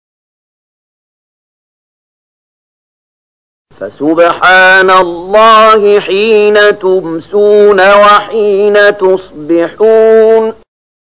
030017 Surat Ar-Ruum ayat 17 dengan bacaan murattal ayat oleh Syaikh Mahmud Khalilil Hushariy: